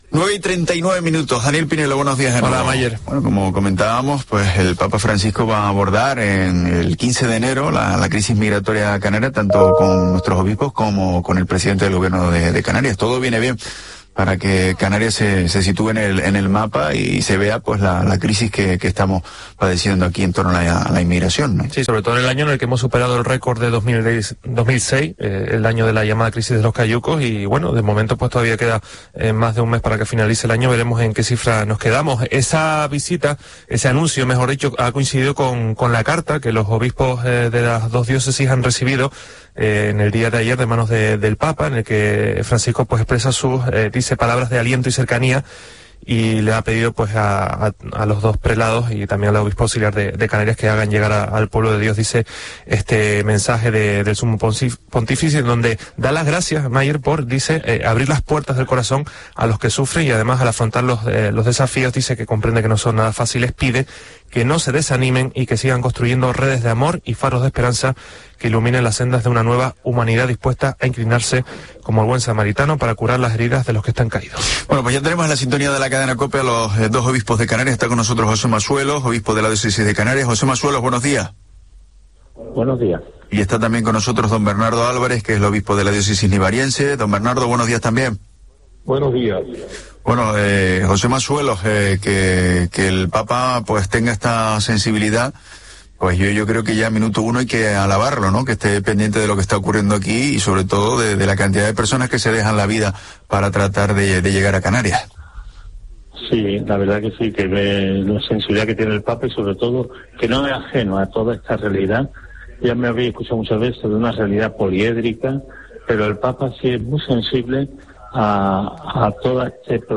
En una entrevista en COPE explican que la diócesis nivariense ha cedido una parte del seminario para acoger a 200 menores y en Las Palmas han cecido...